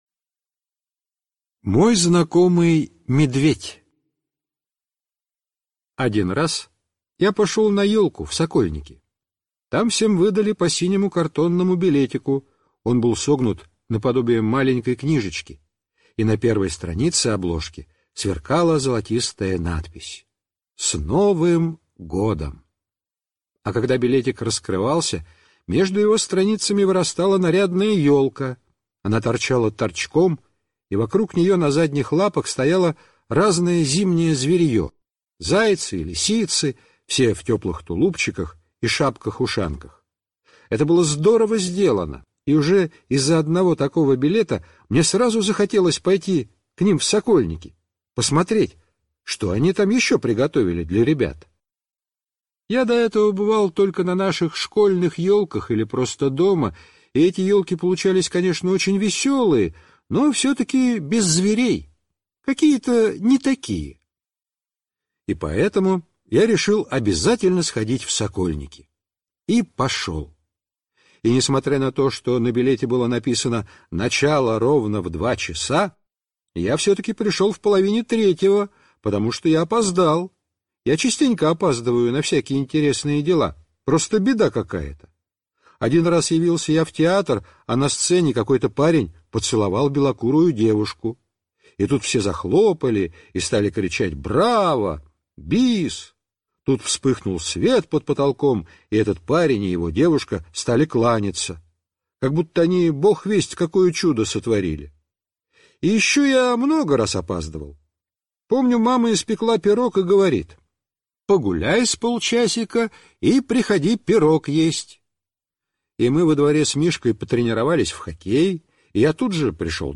Аудио рассказы